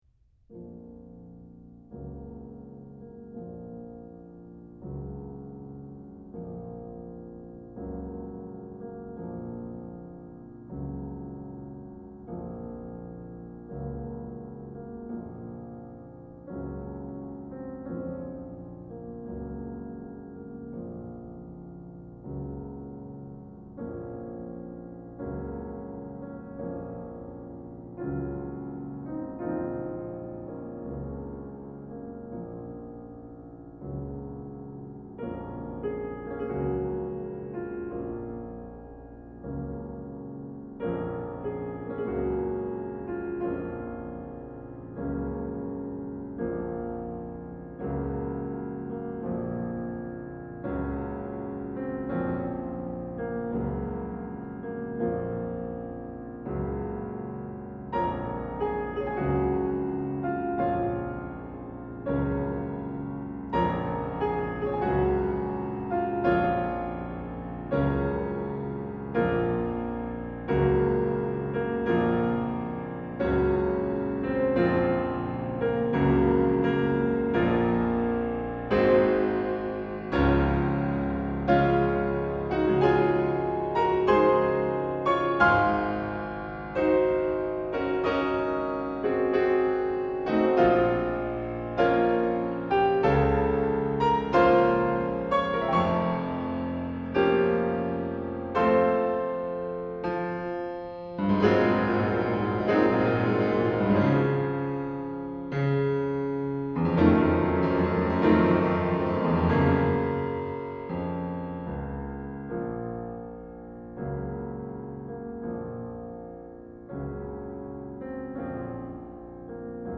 This haunting melody would become synonymous with death.
3. Marche funèbre: Lento ("Funeral March at a slow tempo")
For those interested, this recording was performed by André Watts.
It was so dark and threatening, but then, as I got to know the piece, it has some beautiful and peaceful happy portions as well.
There's a great deal of somber and peaceful emotions captivated in this movement.
And there are some wonderful, rich chords that were typical of mid-to-late 19th century Romantic-era music.
funeral_march3.mp3